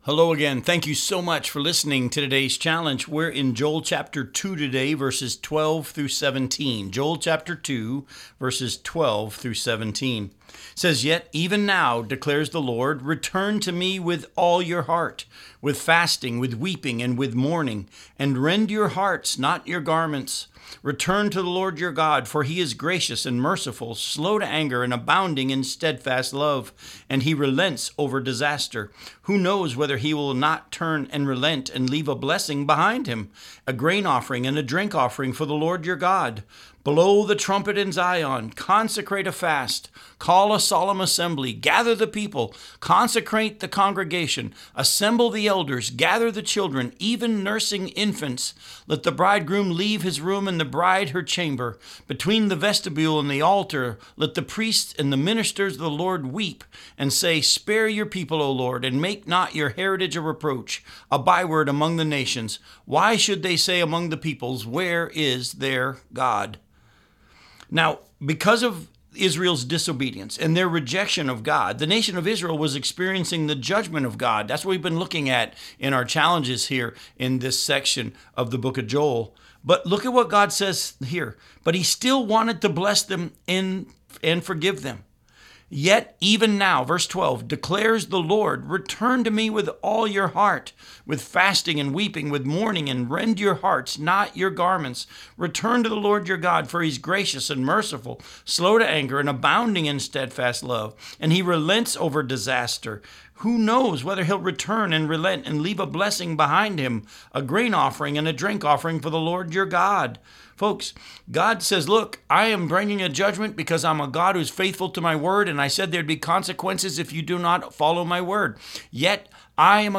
radio program aired on WCIF 106.3 FM in Melbourne, Florida